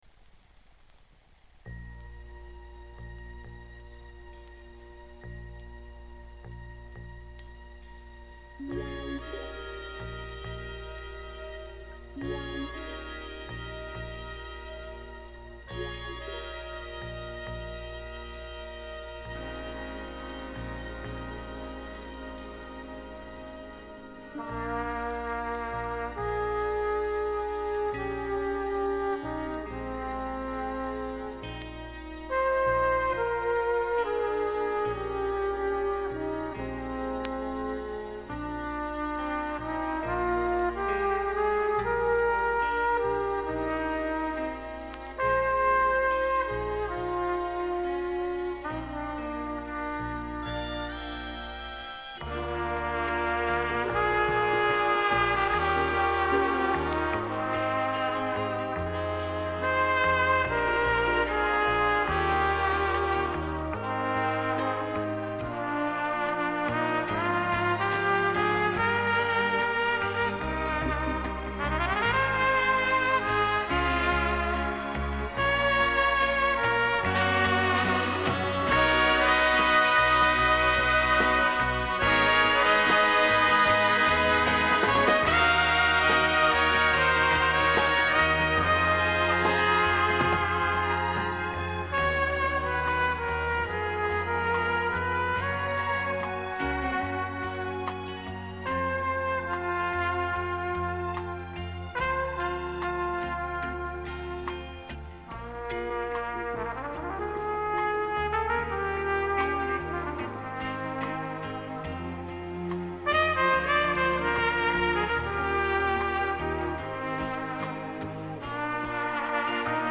Музыкант-трубач из Чехословакии